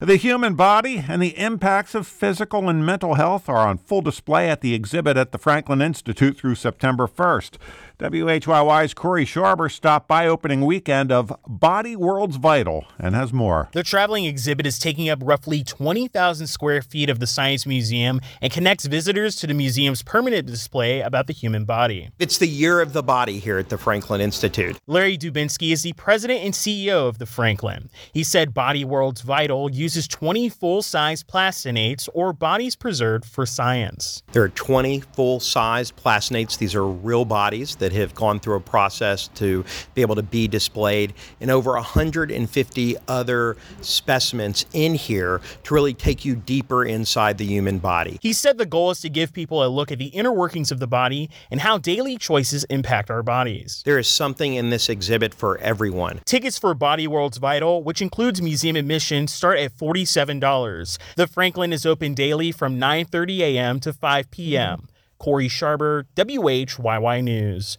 The Pulse invited Yong to come to Philadelphia for a conversation at the Academy of Natural Sciences. He talked about what he learned while writing the book, and he also reflected on what it was like to work on the book during the pandemic, and why he thinks we will be stuck in an era of epidemics and pandemics yet to come.